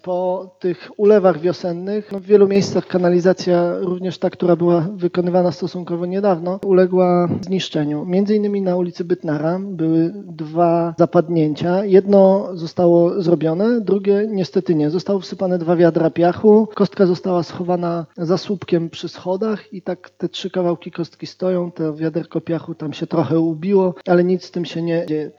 Na przykład zapadnięte studzienki deszczowe na ulicy Janka Bytnara. Mówi mieszkaniec tego rejonu miasta, a zarazem radny Krzysztof Wójcicki: